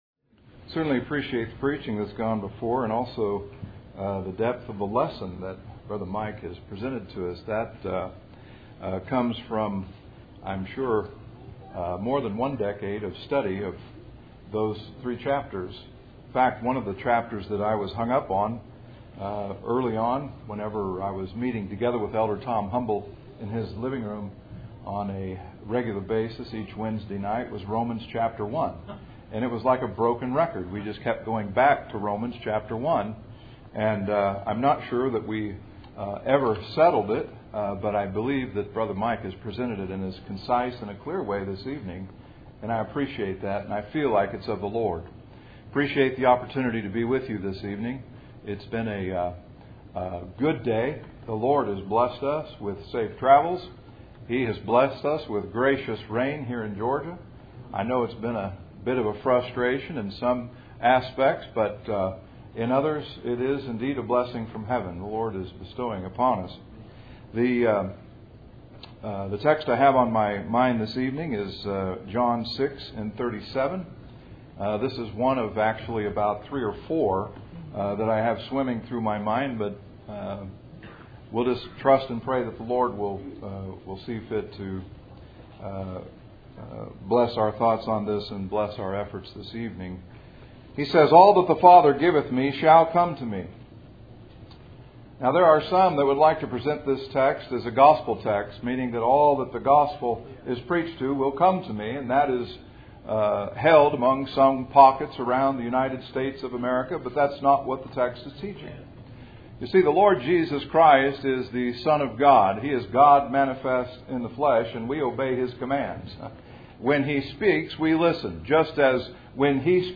Passage: John 6:37-40 Service Type: Cool Springs PBC August Annual Meeting %todo_render% « 3 Things Manifested by Paul